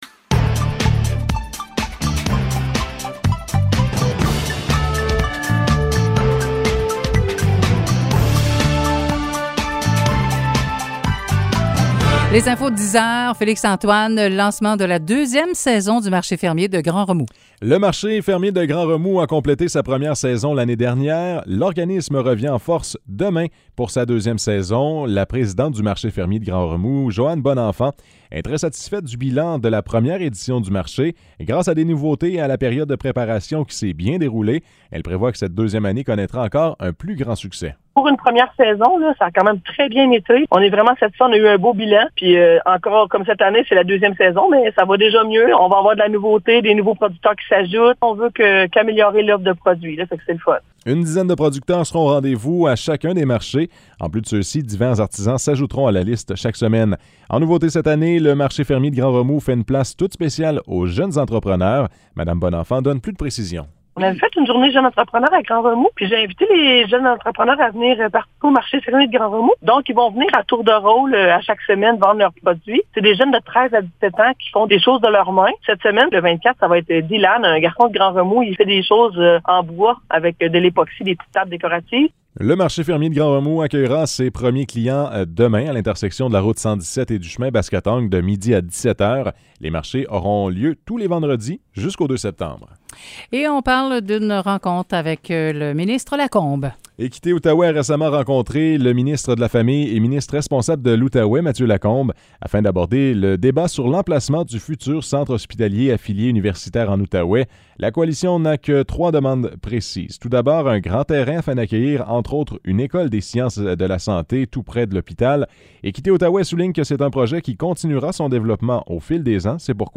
Nouvelles locales - 23 juin 2022 - 10 h